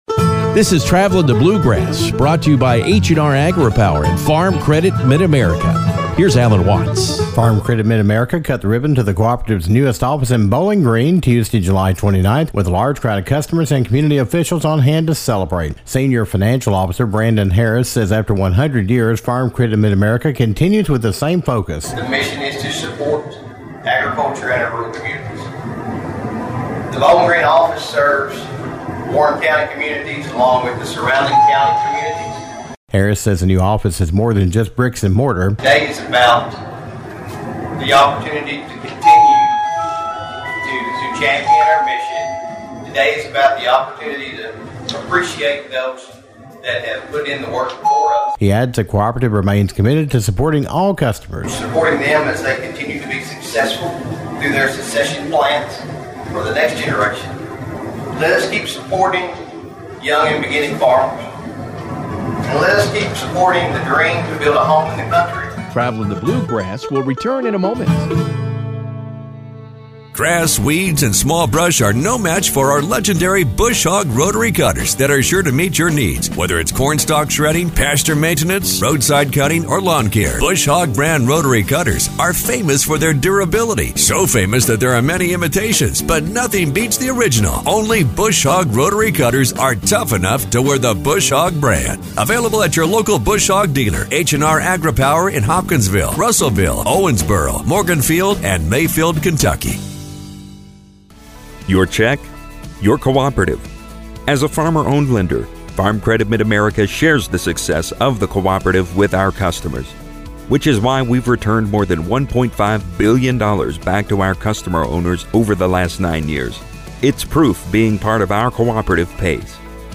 Farm Credit Mid-America officials were joined by farmers and officials in Warren County July 29th as they cut the ribbon to the new office in Bowling Green.